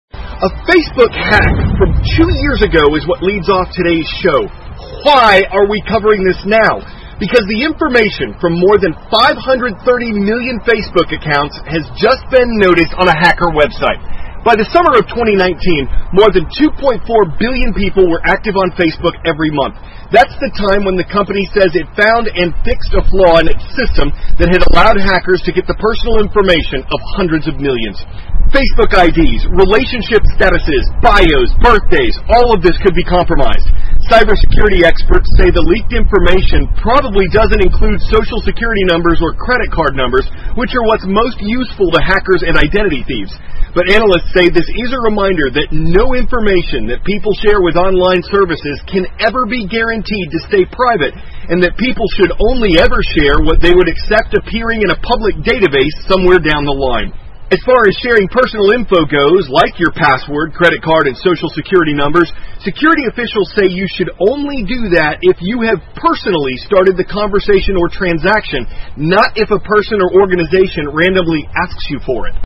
美国有线新闻 CNN 5.3亿Facebook用户信息在黑客网站被泄露 听力文件下载—在线英语听力室